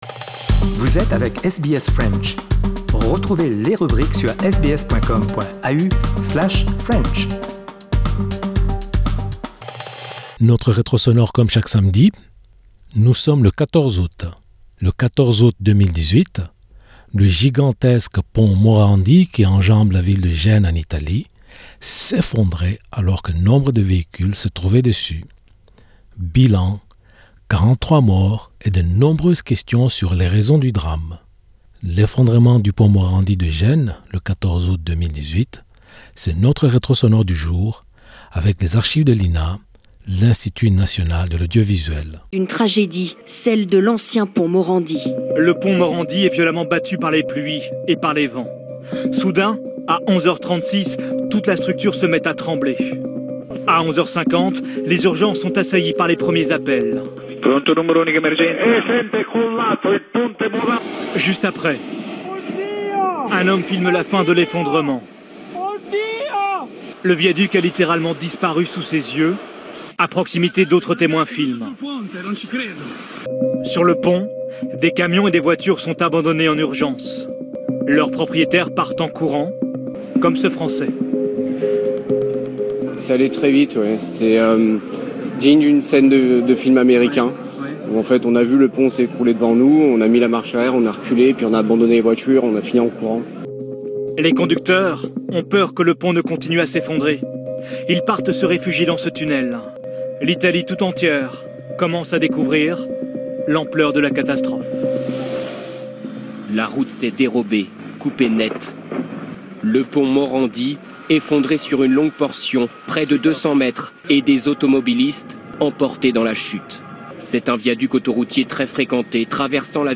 Bilan : 43 morts et de nombreuses questions sur les raisons du drame… L'effondrement du Pont Morandi de Gênes… le 14 août 2018... c'est notre retro sonore du jour avec les archives de l'INA... l'Institut National de l'Audiovisuel.